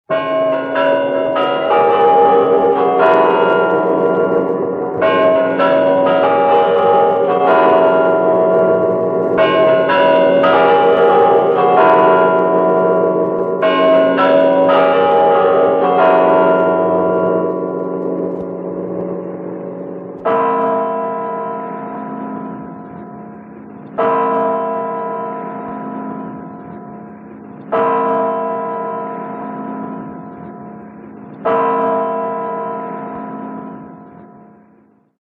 Куранты.mp3